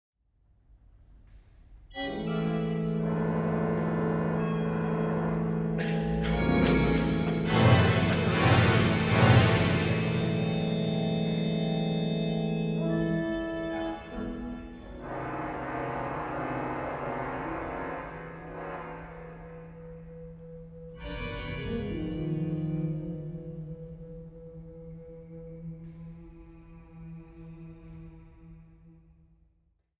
Peter-Orgel der Kunst-Station Sankt Peter Köln